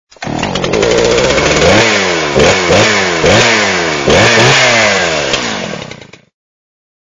Kategorie Efekty Dźwiękowe